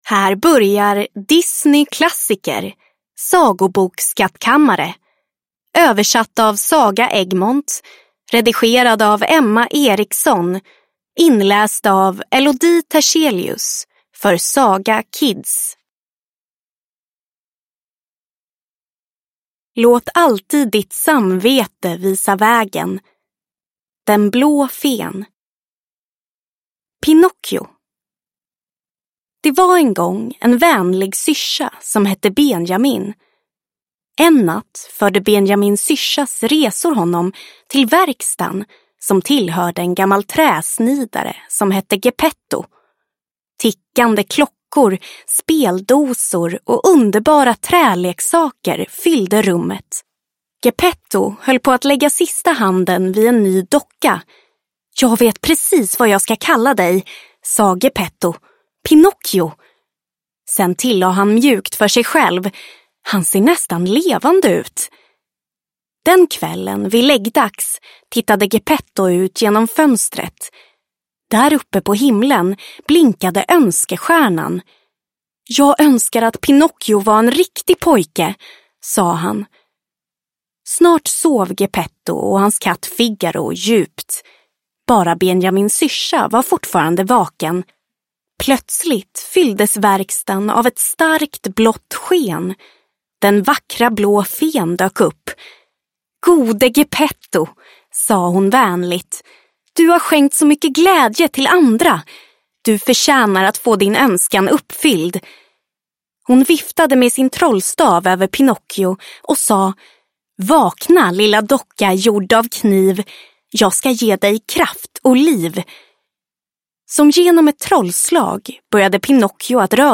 Disney Klassiker – Sagoboksskattkammare (ljudbok) av Disney